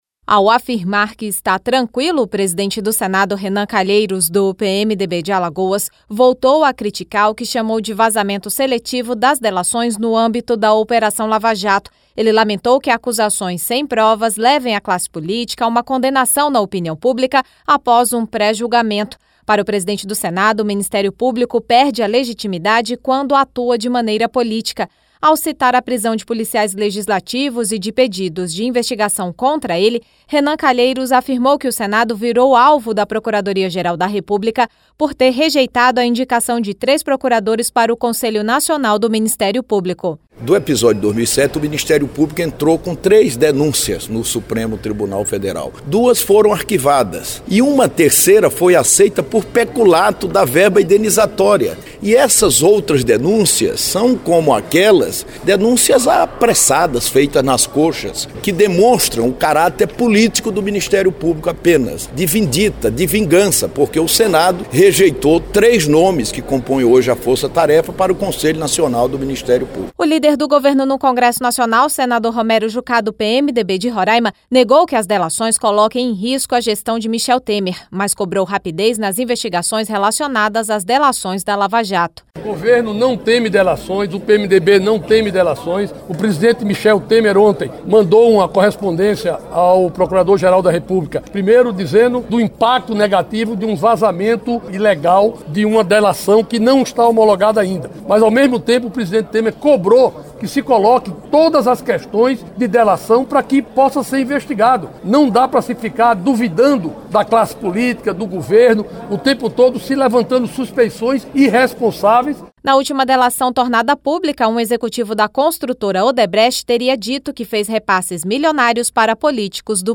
Presidente do Senado Federal, senador Renan Calheiros (PMDB-AL), concede entrevista.